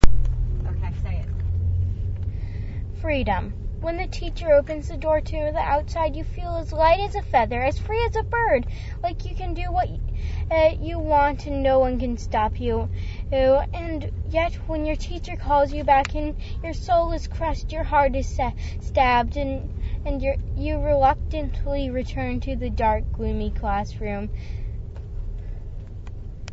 Daily Dose of Poetry – Spoken Word